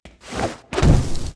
skill_fire_blast_a.wav